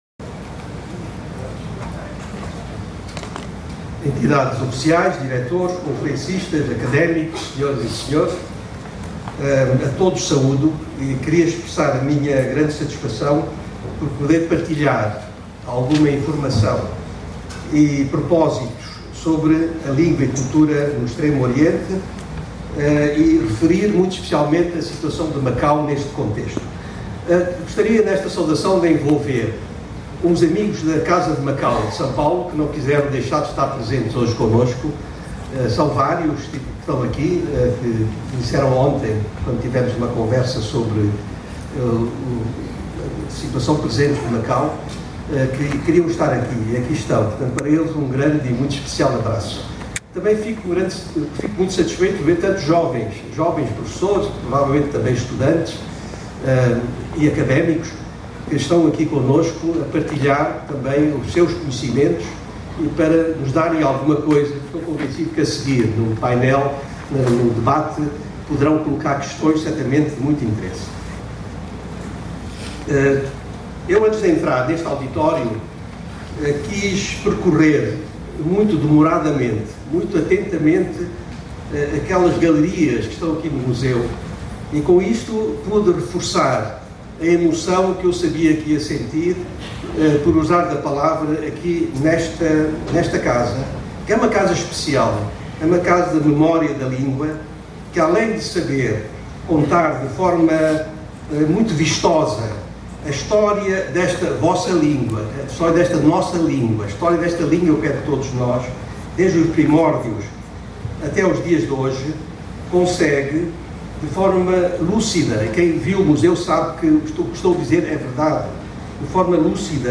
Palestra de Jorge Rangel no Seminário sobre a língua portuguesa em São Paulo
Clique na seta acima para ouvir a palestra proferida por Jorge Rangel no Seminário – Português, Nova Língua de Poder e de Economia – sobre o tema: Língua e Cultura no Extremo Oriente, um legado valorizado.
jorge-rangel-palestra-lingua-e-cultura-no-extremo-oriente.mp3